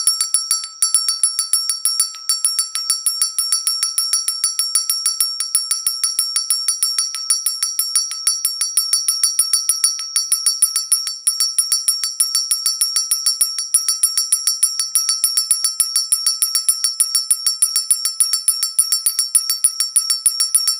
Zvonček so srdiečkami biely